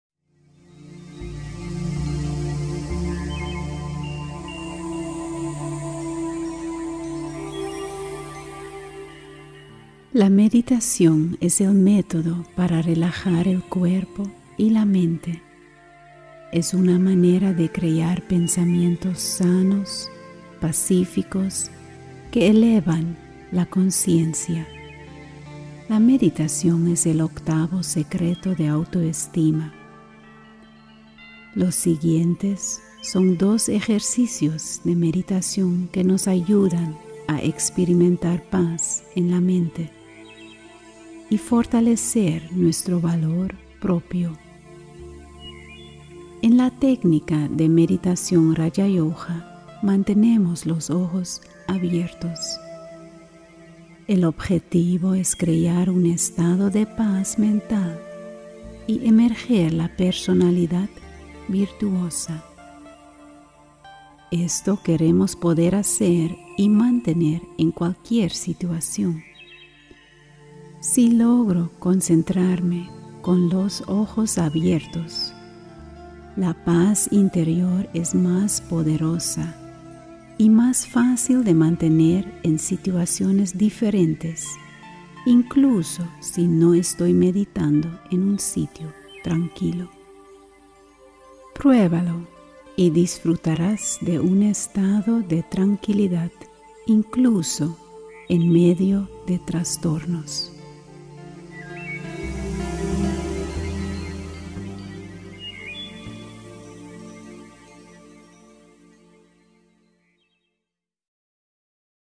Genre Meditaciones Guiadas